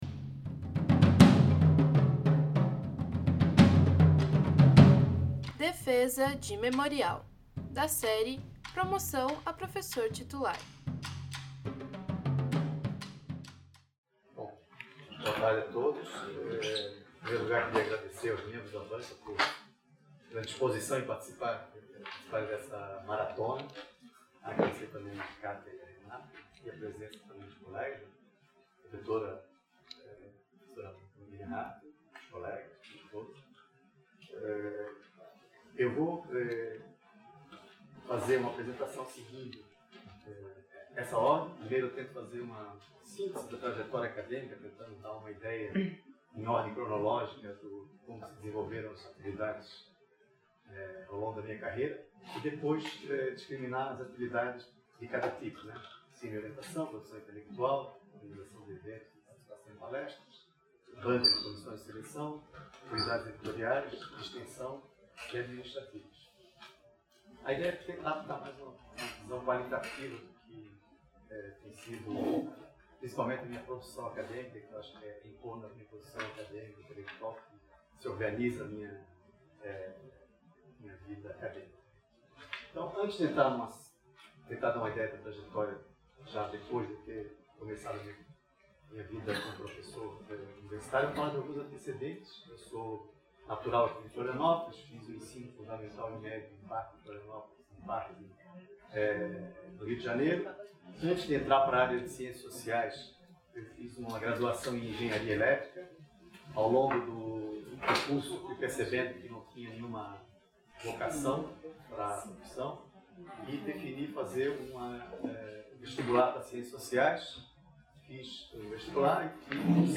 na sala 110 do Departamento de Antropologia. Área de atuação: Estudos eleitorais e Partidos políticos.